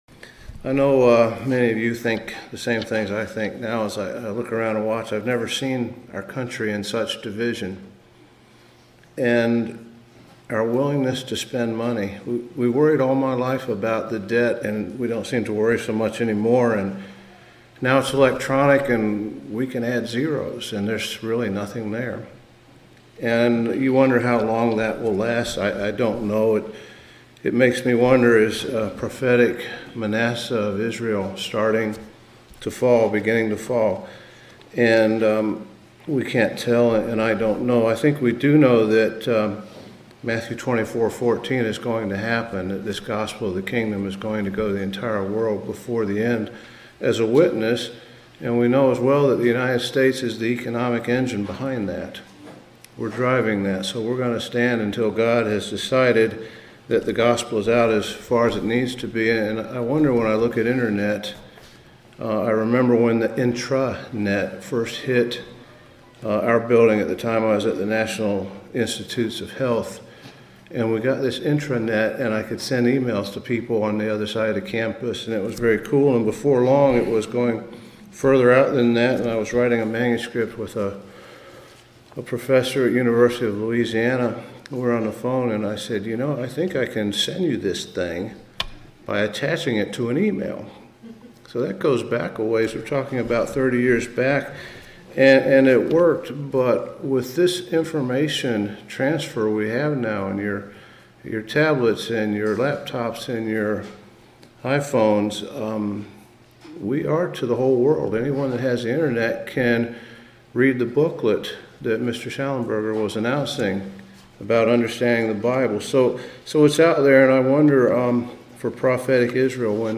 split-sermon